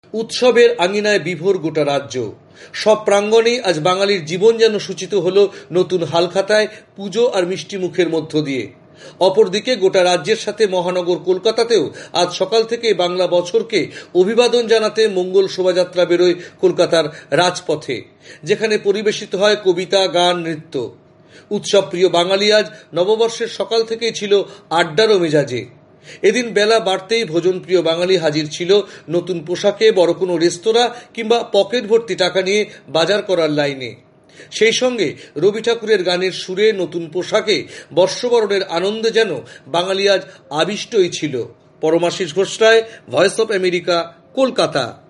কলকাতা থেকে বিস্তারিত জানাচ্ছেন